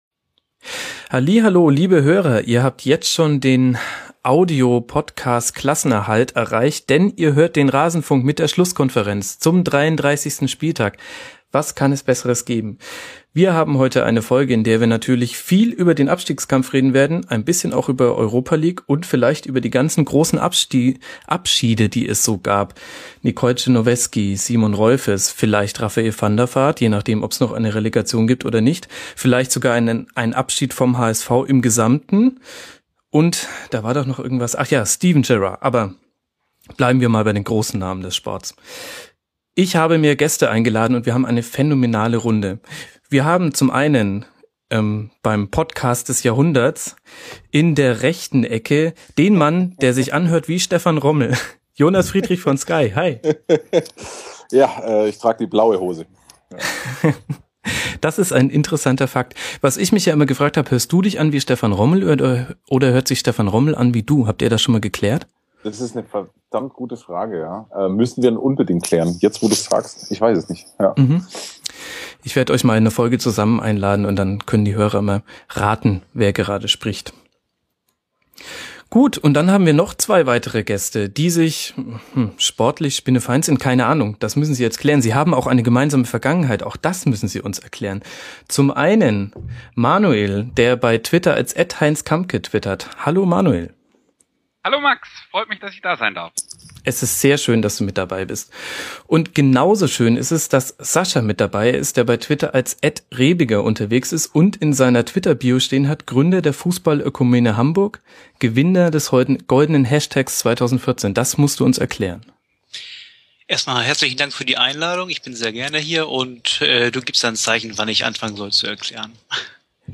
Es wird profan-poetisch in der Schlusskonferenz!